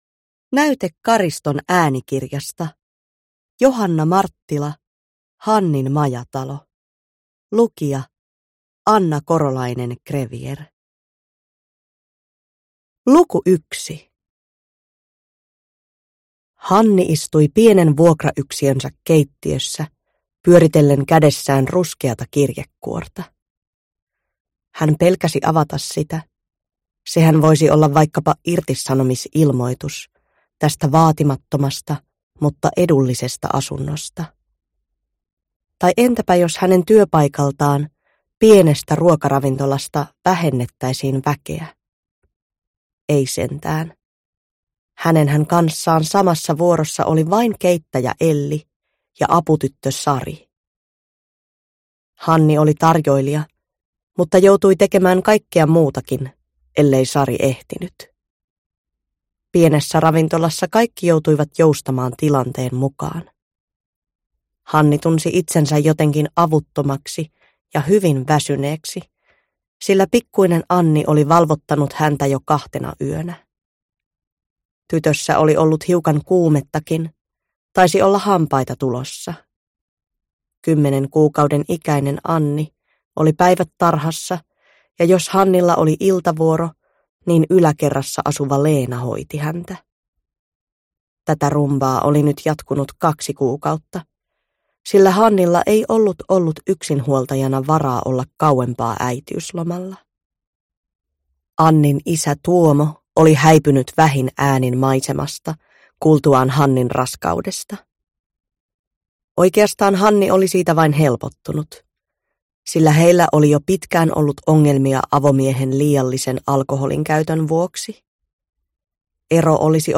Hannin majatalo (ljudbok) av Johanna Marttila